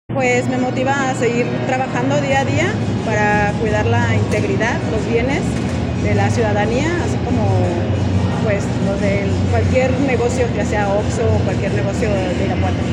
integrante de Policía Municipal